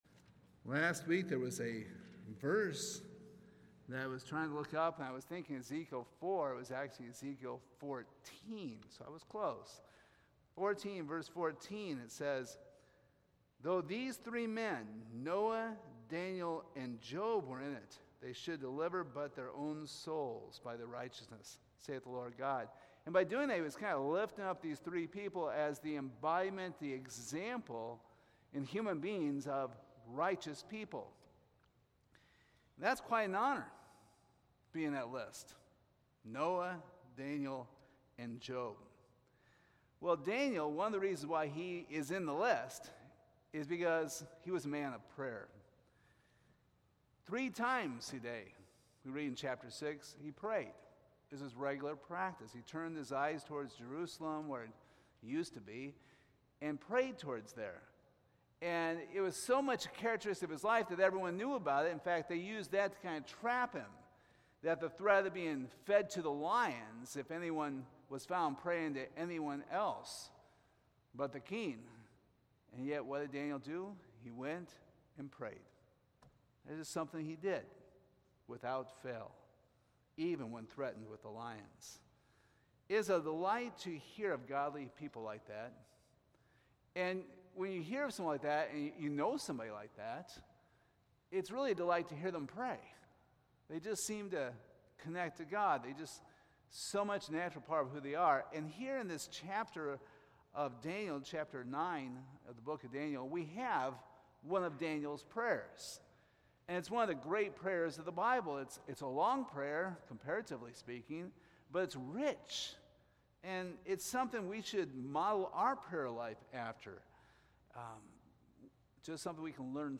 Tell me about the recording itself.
Daniel 9 Service Type: Sunday Evening This is part 1 of a 2-part series on Daniel's prayer.